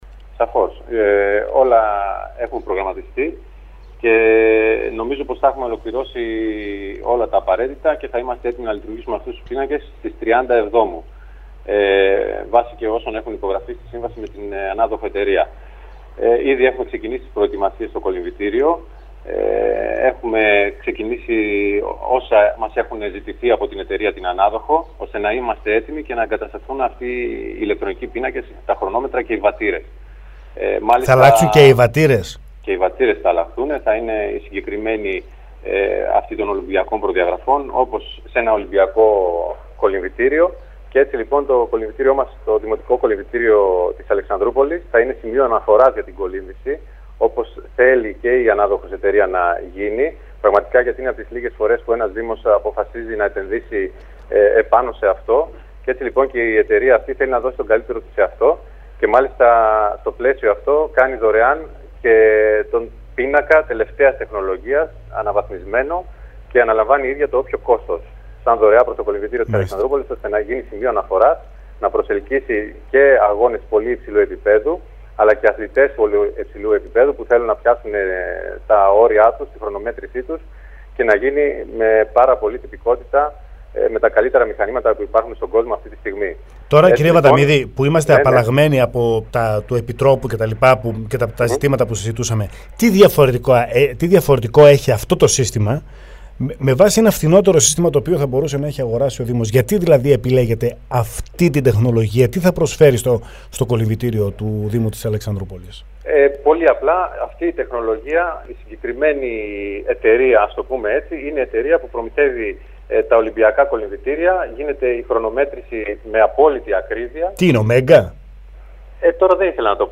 στο ραδιόφωνο Sferikos 99,3.